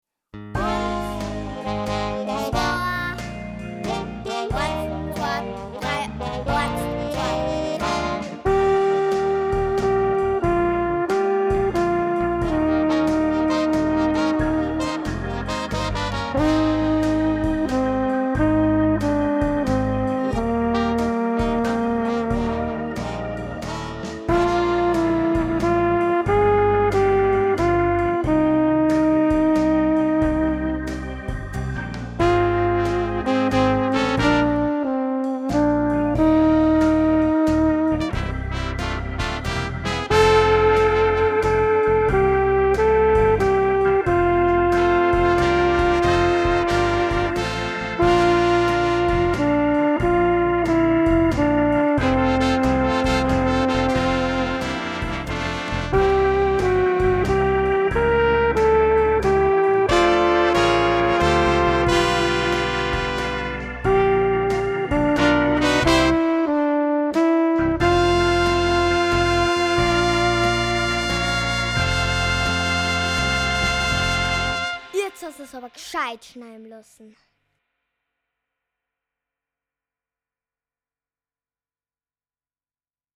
Besetzung: Trompete